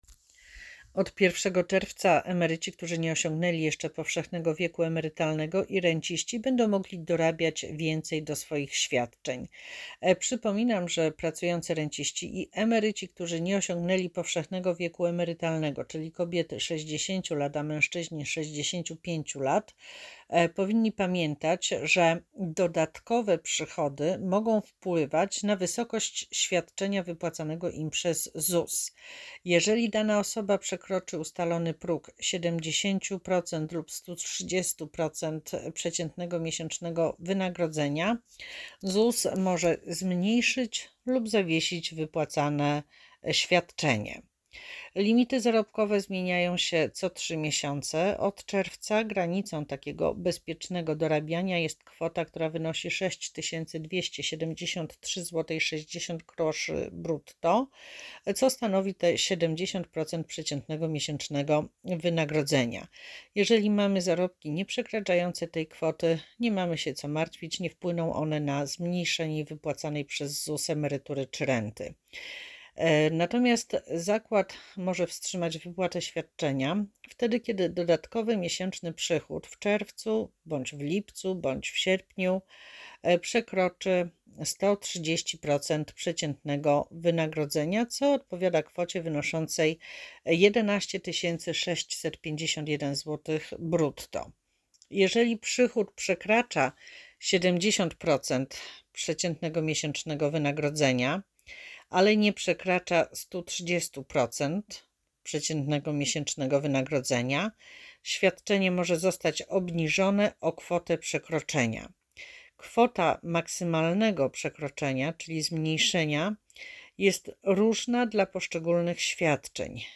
Audiodeskrypcja_123.mp3